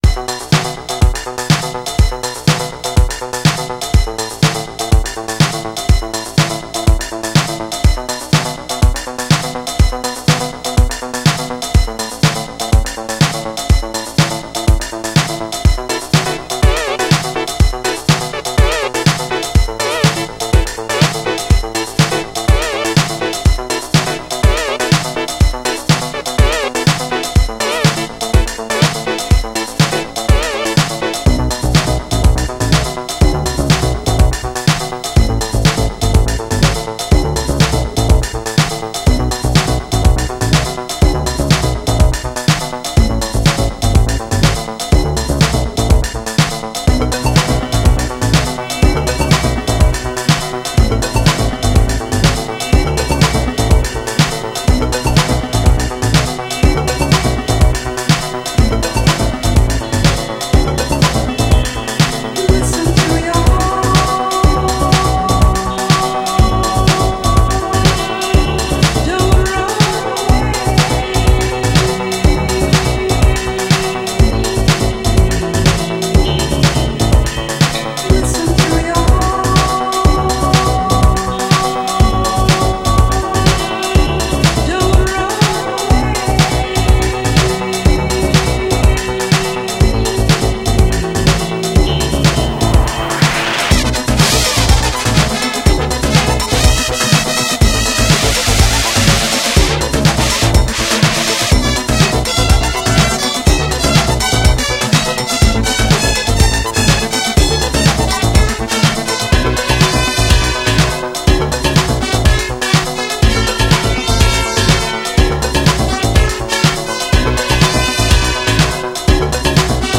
Жанр:Electronic